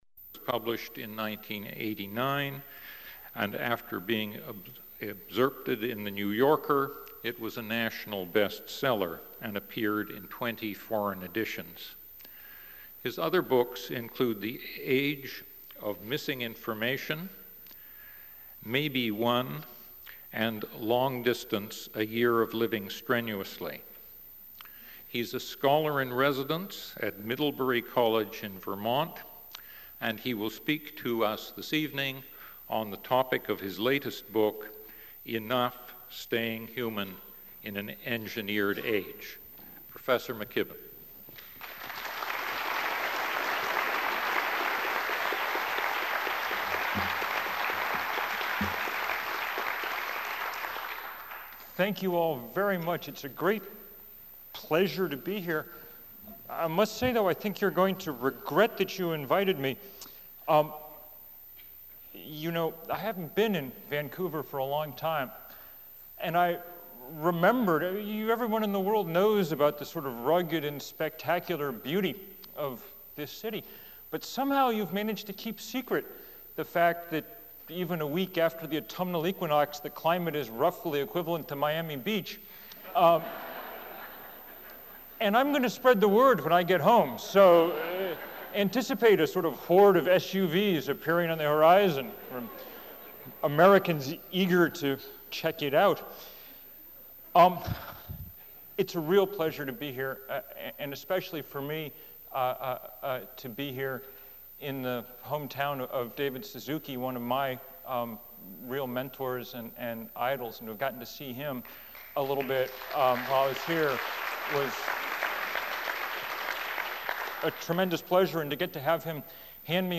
Item consists of a digitized copy of an audio recording of a Vancouver Institute lecture given by William McKibben on September 27, 2003.